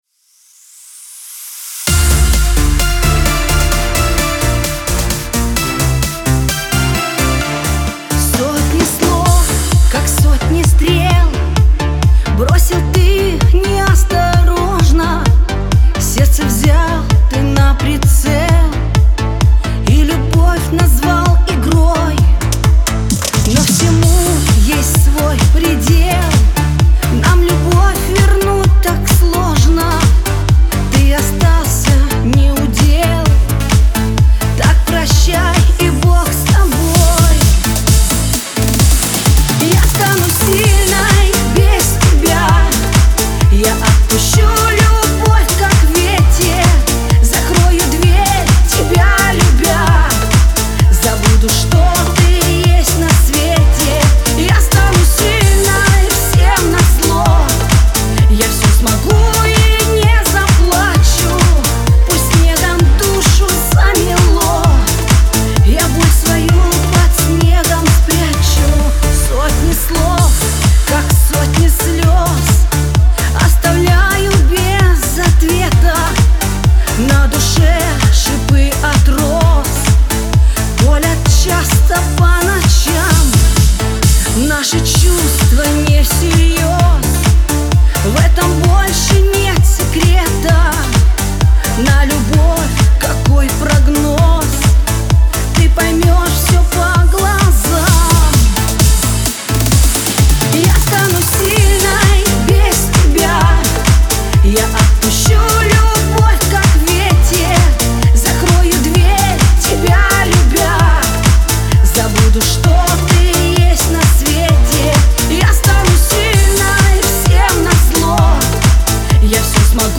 диско
pop